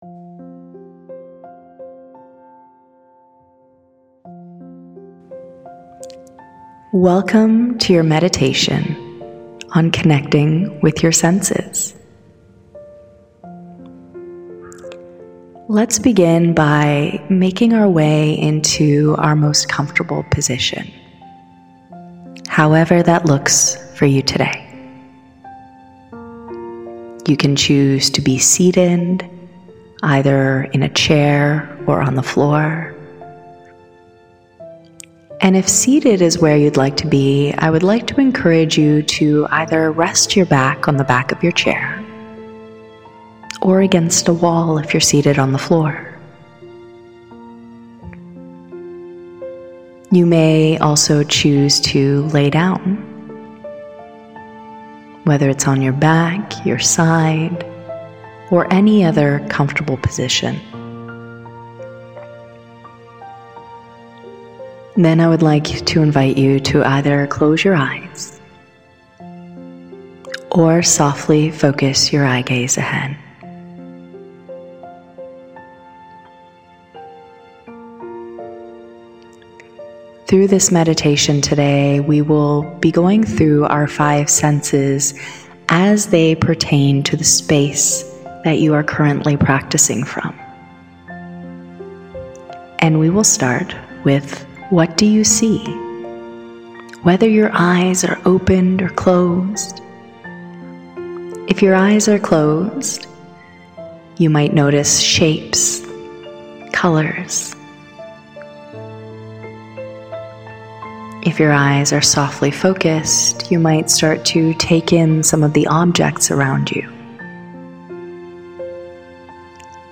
Here’s what science (and experience) say—and a free guided meditation to try for yourself.